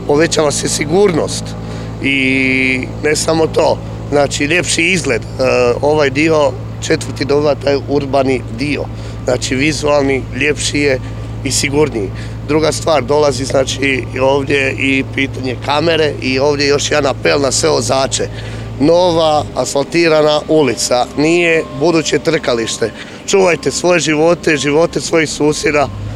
Stipe Milas, predsjednik Gradske četvrti 3, zahvalio je stanovnicima ove ulice na strpljenju, kao i svima koji su bili uključeni u realizaciju ovoga projekta